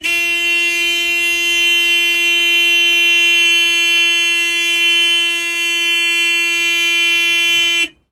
Звуки гудка автомобиля
Длительный звук автомобильного сигнала